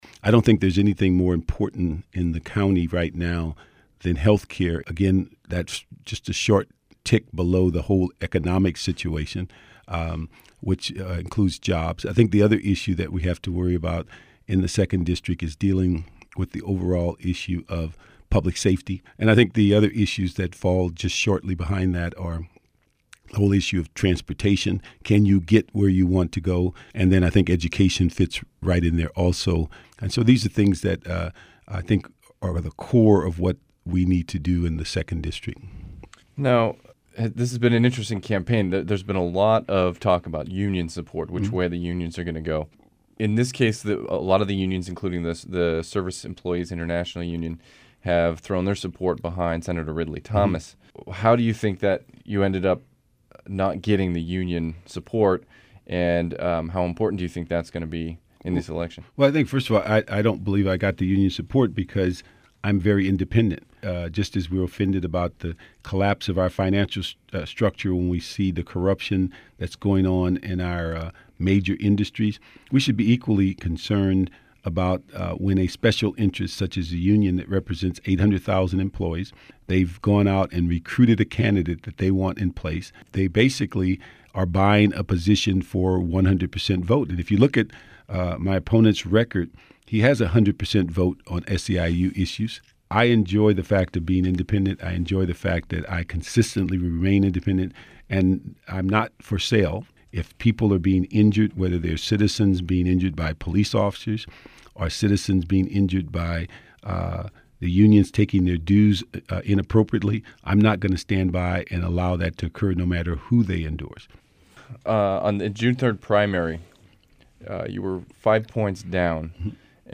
bernardparksinterview.mp3